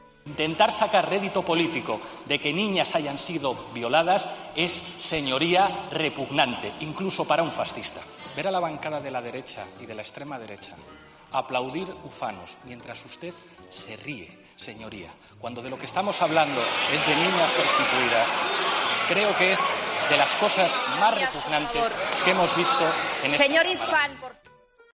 Pues la de ayer fue en el Congreso de los Diputados, de telonero de Sánchez. El tema central de la performance fue el presunto abuso a menores tuteladas en un centro de Baleares.
Palabras de Pablo Iglesias